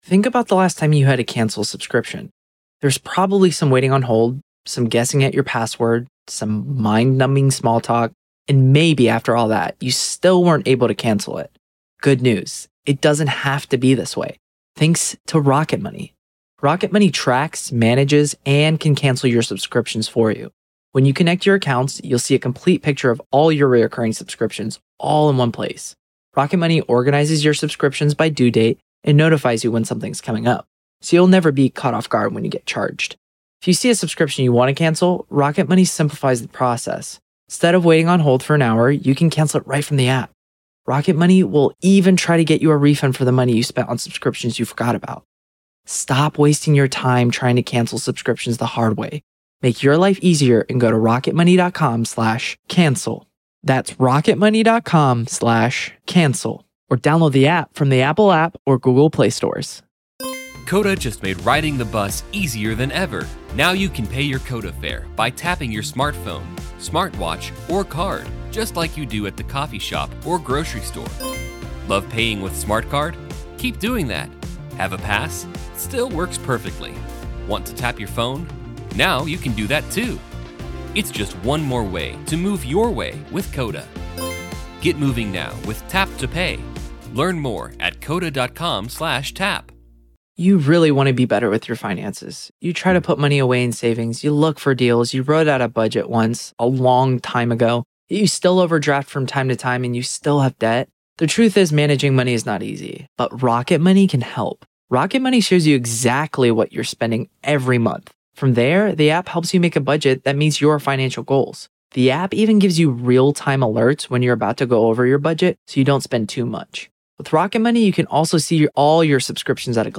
This full-length interview